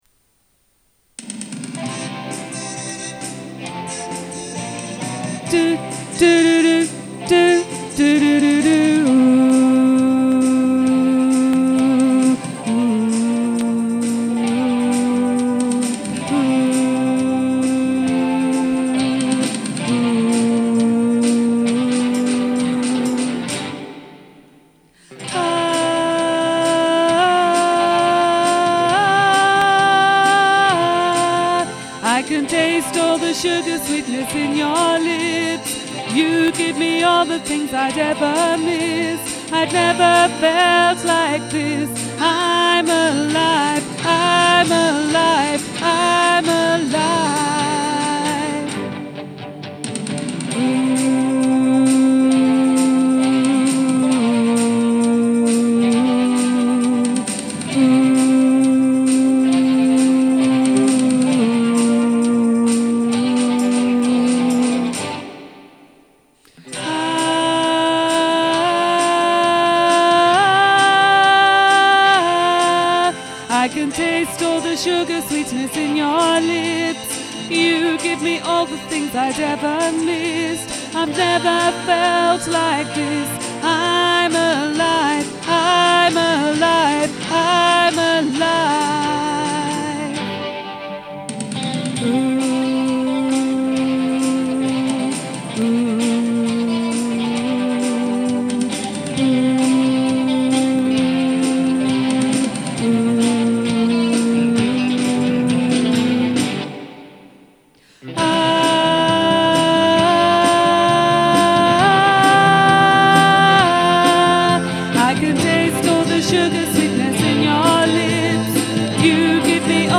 Im-Alive-Alto.mp3